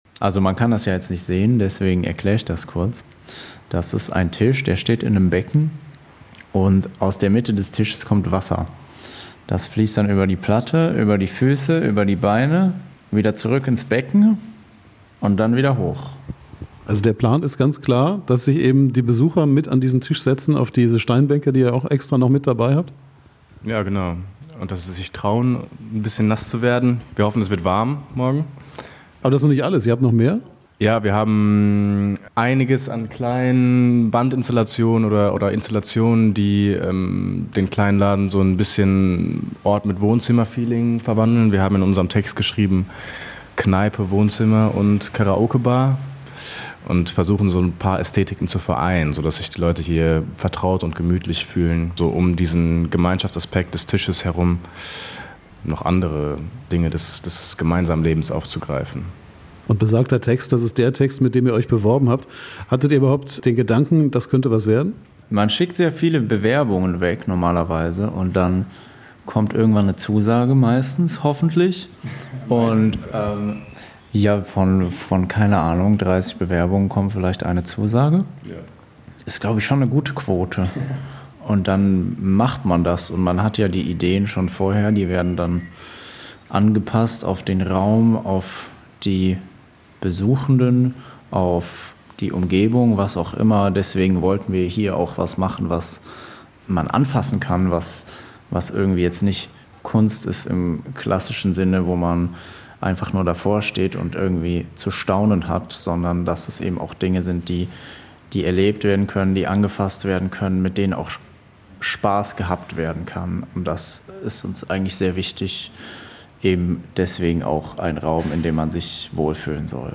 Ein Interview in einem noch trockenen Springbrunnen